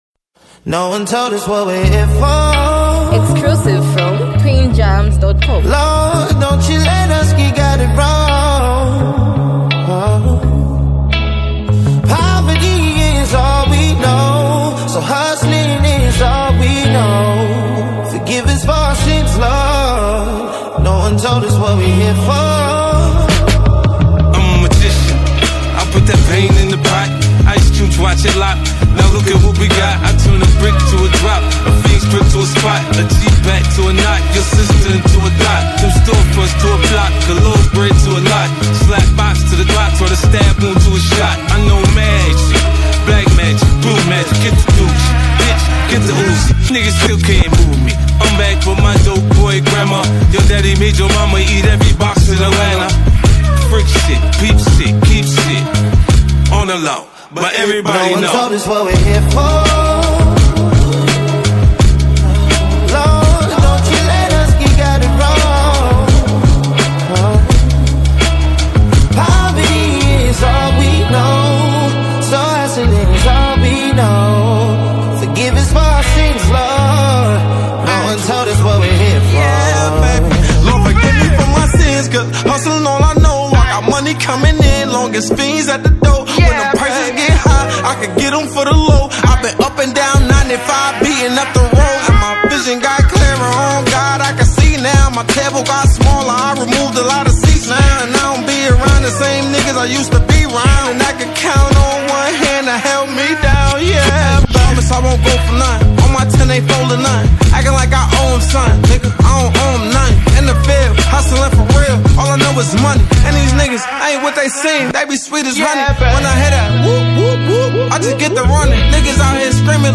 modern hip-hop